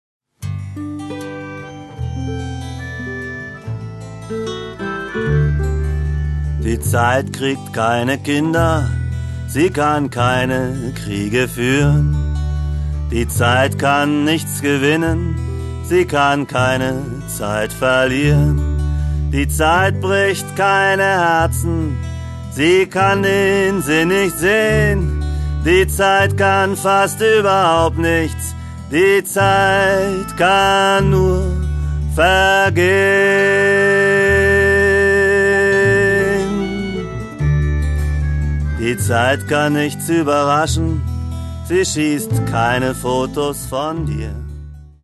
Ich mache deutschsprachige Lieder zur Gitarre.